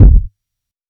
Kick CORSO.wav